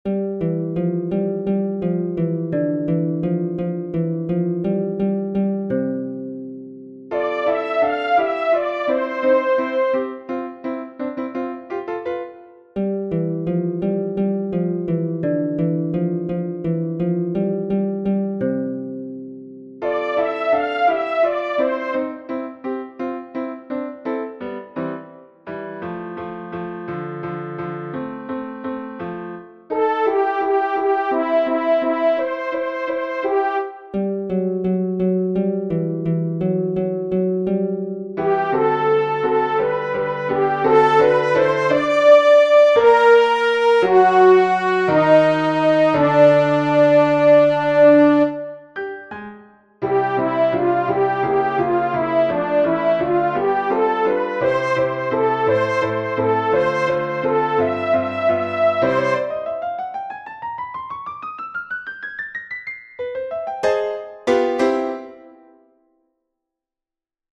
The baritone soloist sounds like a harp. The featured voice is a horn.
SOPRANO 1